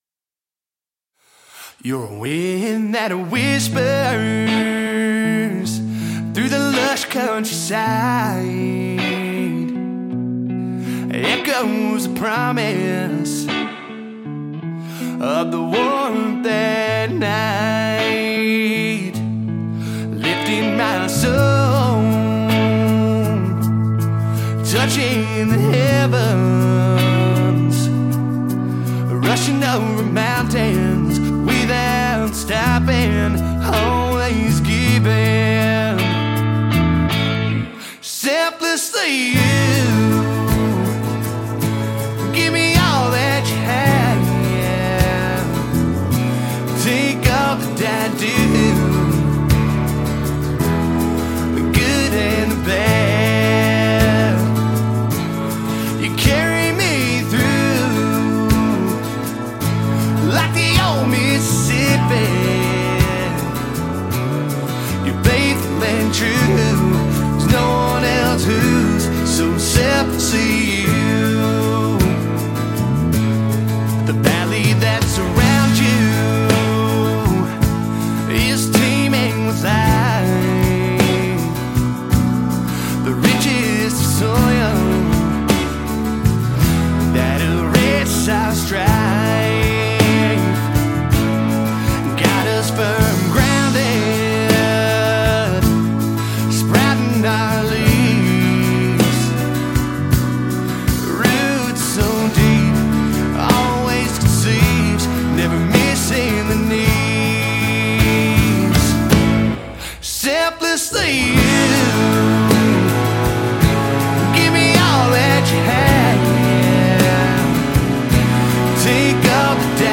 Country Singer/Songwriter
a soulful, emotionally rich country single
SONG GENRE – Country/Texas Country